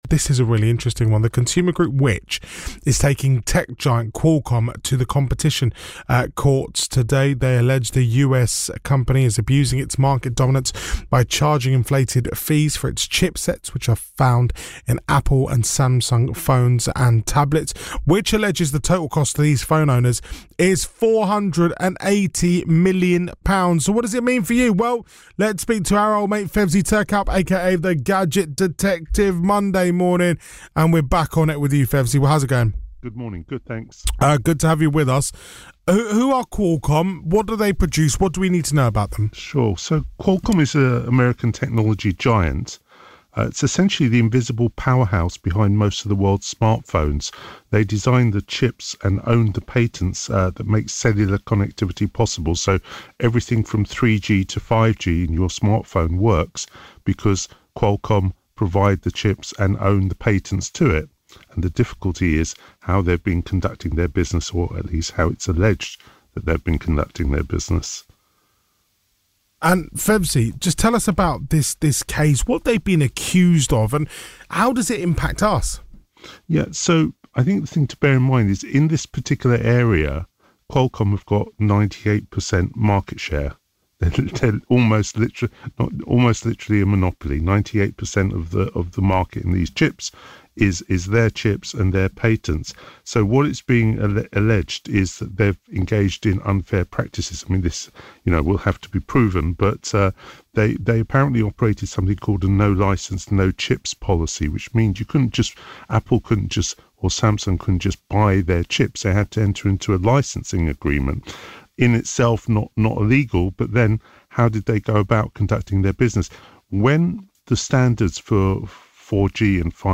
6th October 2025 - Which Lawsuit against Qualcomm on BBC Radio London 4:45